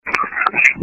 Common EVP Phrases
Are Phrases We Often Hear When Recording EVP